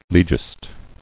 [le·gist]